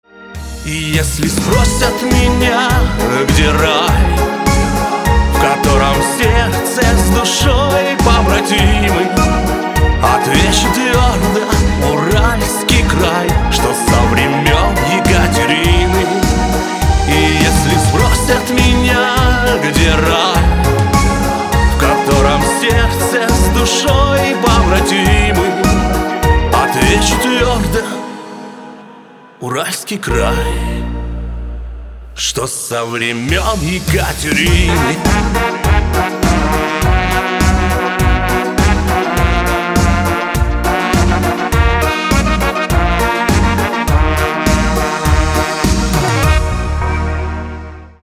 • Качество: 320, Stereo
мужской вокал
русский шансон
патриотические